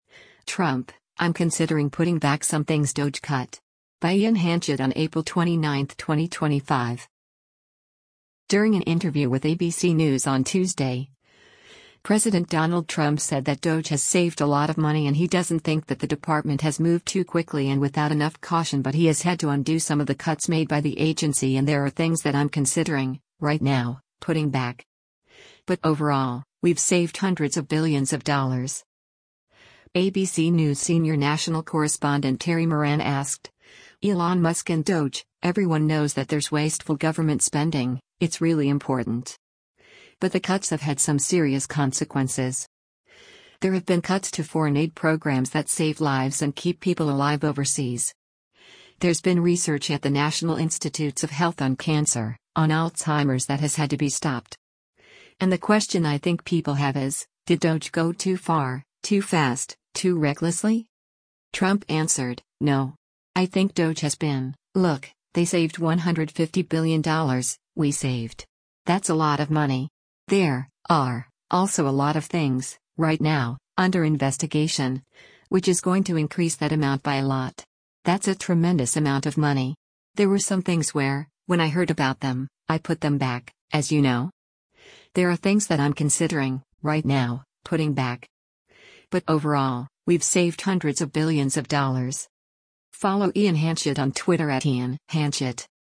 During an interview with ABC News on Tuesday, President Donald Trump said that DOGE has saved a lot of money and he doesn’t think that the department has moved too quickly and without enough caution but he has had to undo some of the cuts made by the agency and “There are things that I’m considering, right now, putting back. But overall, we’ve saved hundreds of billions of dollars.”